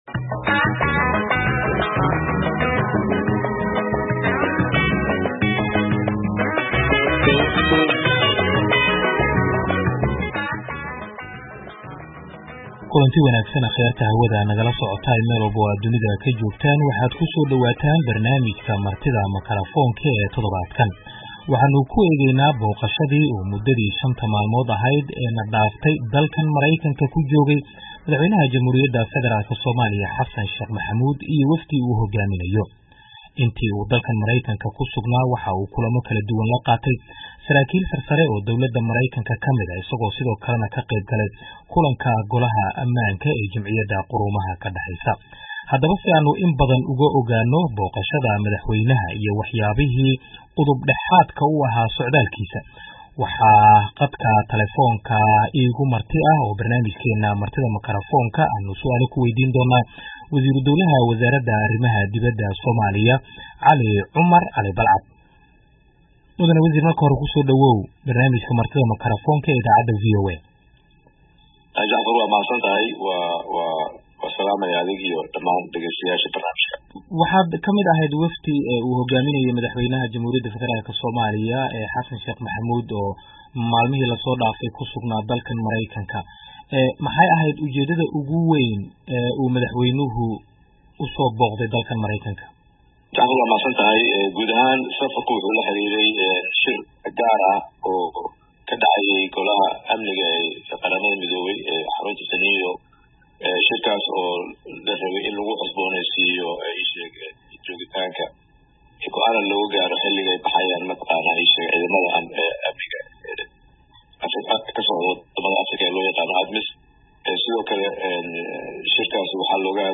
WASHINGTON - Barnaamijka Martida Makarafoonka, waxaaa toddobaadkan marti ku ah Cali Cumar (Cali Balcad) oo ah Wasiiru-dowlaha Arrimaha Dibadda ee Soomaaliya, waxaana uu ku saabsan yahay booqashadii madaxweyne Xassan Sh. Maxamuud uu ku tagay Mareykanka iyo hadalkii uu ka jeediyay shirka Golaha...